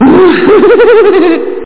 LAUGH7.mp3